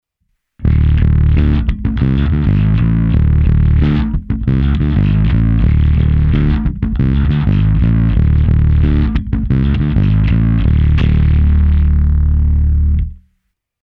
Ve zvuku díky čistému signálu nechybí basy, ale nejsou ani přehnané, a hlavně nejsou tolik potlačeny výšky jako u jiných simulací.
Udělal jsem nahrávky, kdy jsem použil baskytaru Music Man StingRay 5, kompresor TC Electronic SpectraComp a preamp Darkglass Alpha Omega Ultra.
Čistý zvuk se simulací